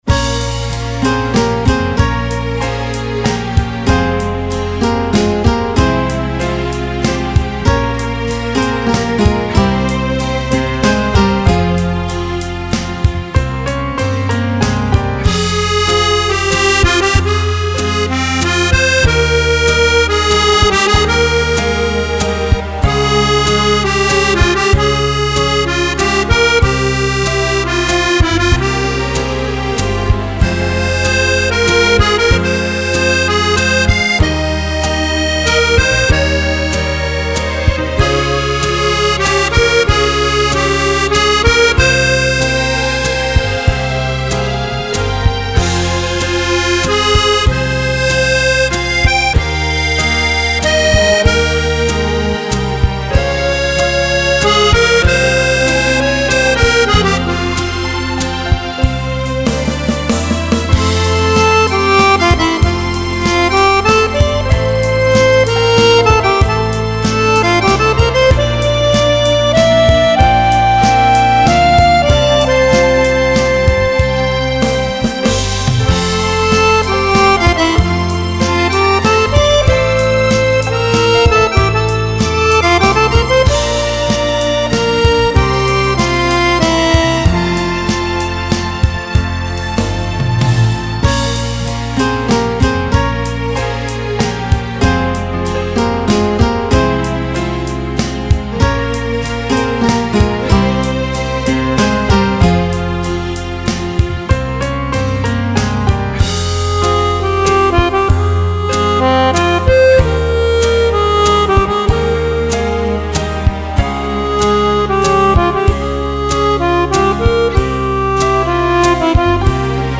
Valzer lento
Valzer lento per Fisarmonica